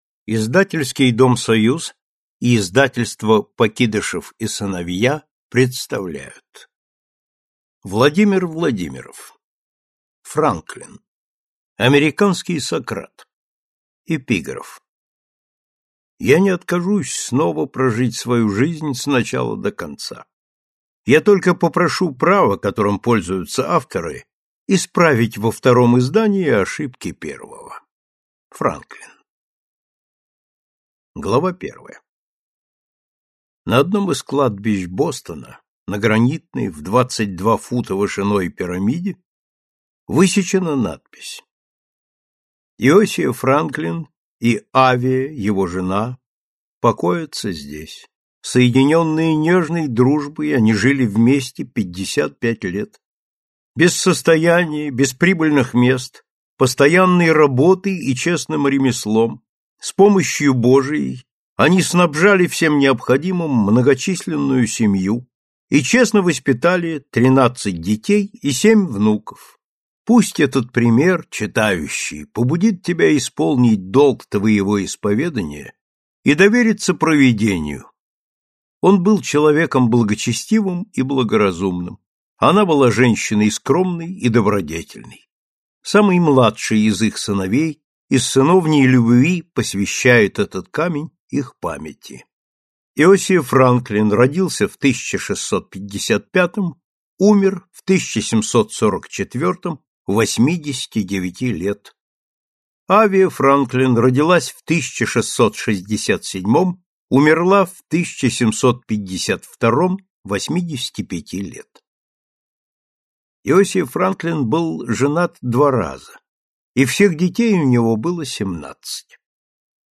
Аудиокнига Франклин | Библиотека аудиокниг